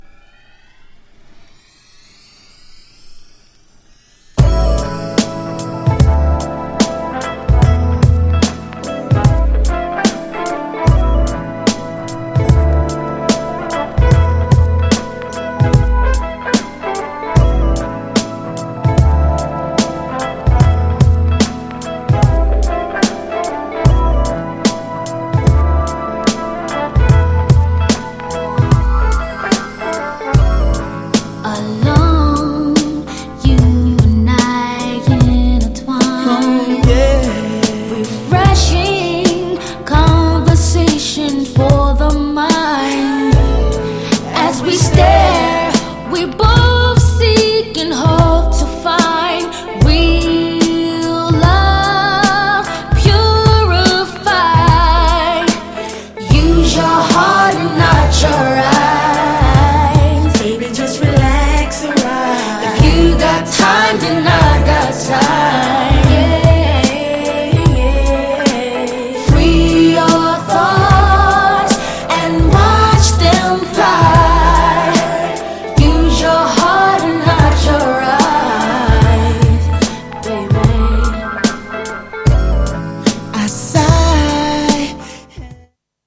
GENRE R&B
BPM 91〜95BPM